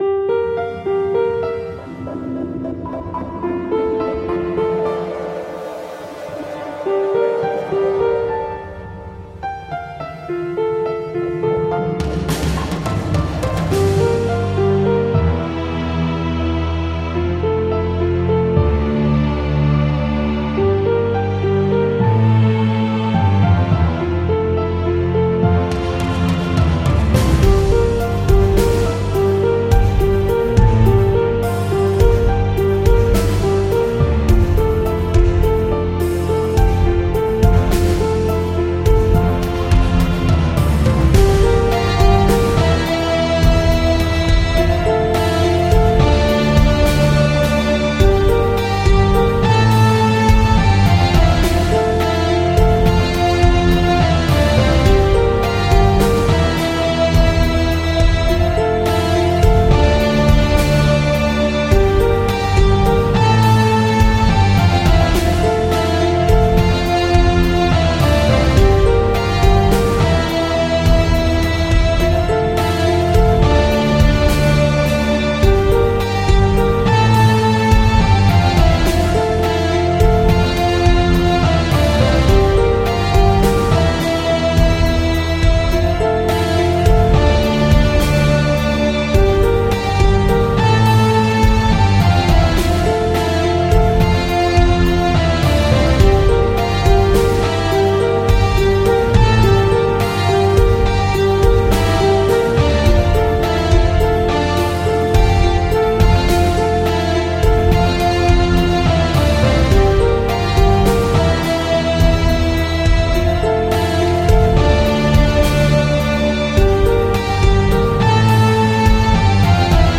Музыка для титров с плохой концовкой